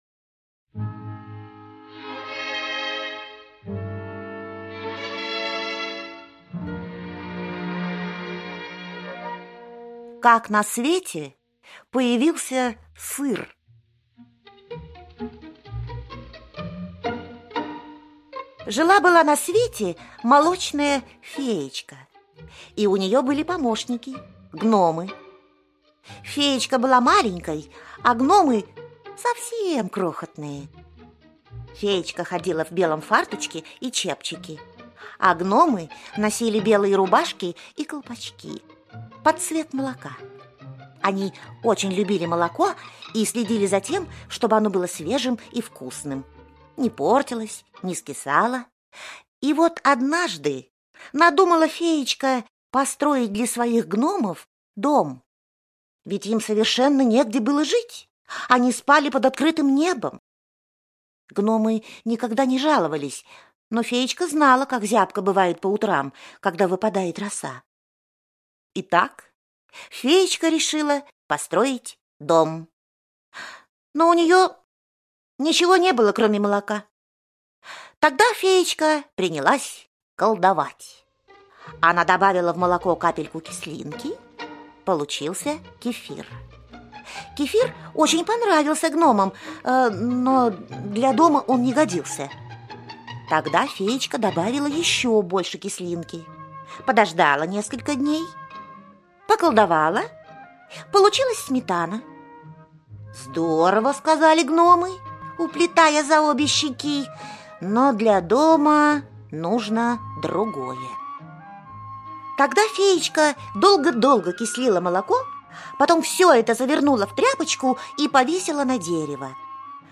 Слушайте Как на свете появился сыр - аудиосказка Онисимовой О. Жила на свете маленькая молочная феечка.